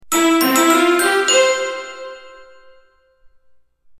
Stylish Digital Notification Sound Effect
A sleek and trendy phone tone with a crisp, clean sound. Perfect for apps, messages, alerts, or user interface notifications.
Stylish-digital-notification-sound-effect.mp3